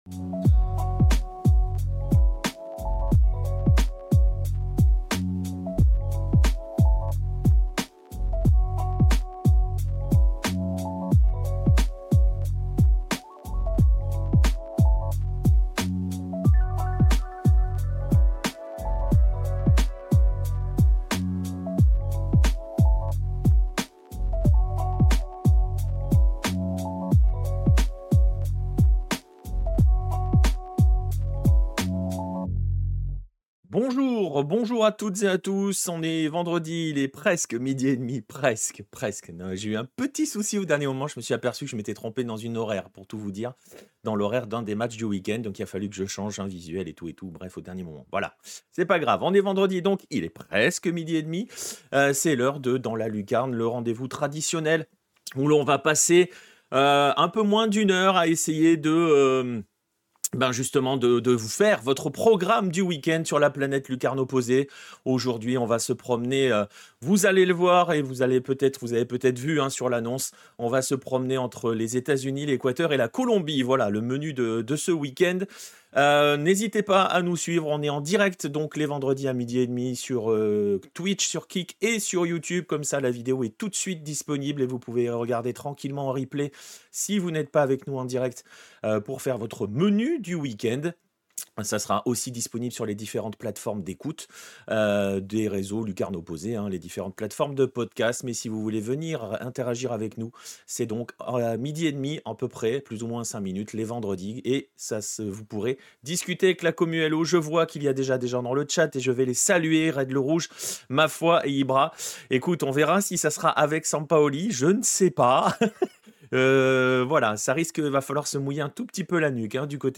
🎵 Musique Dreamy Lo-Fi| Chill Vibes